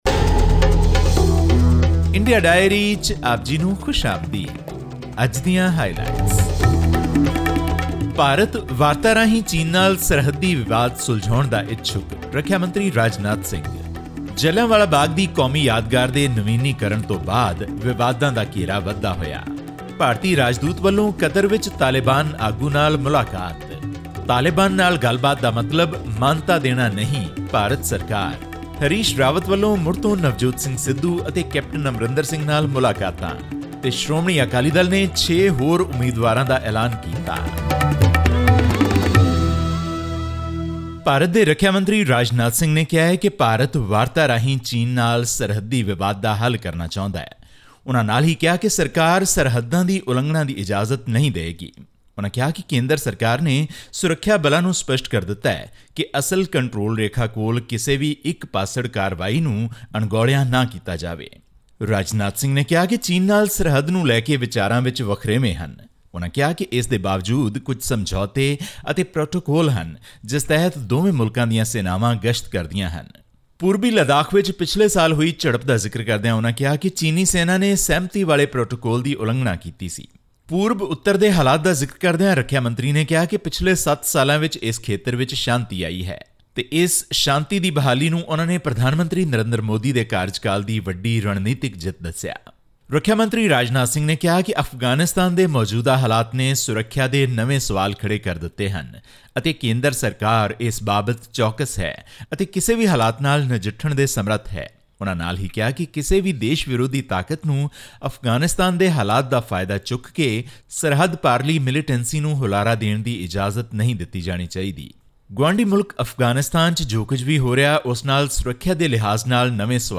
The renovation of the historical Jallianwala Bagh memorial in Amritsar in Punjab, where more than 1,000 people died 102 years ago, has sparked outrage among historians, many of whom have expressed concerns over history being erased. This and more in our weekly news segment from India.